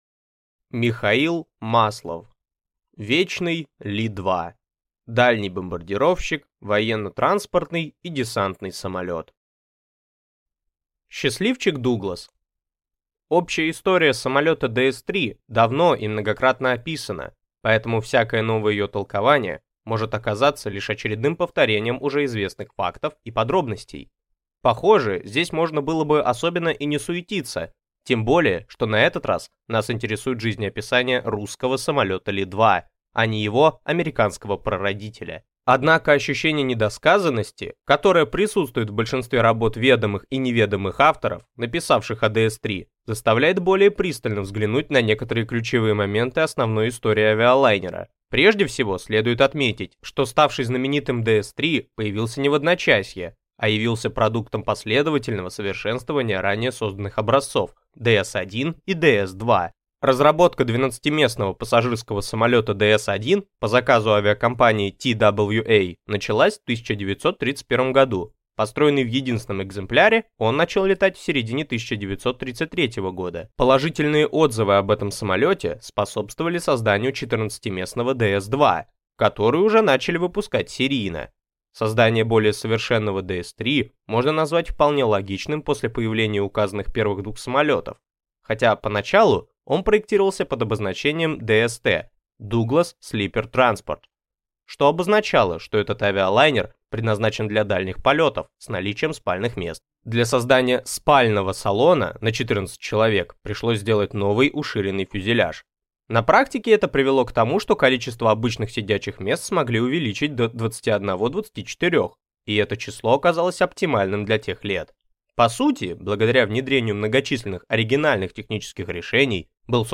Аудиокнига «Вечный» Ли-2 – дальний бомбардировщик, военно-транспортный и десантный самолет | Библиотека аудиокниг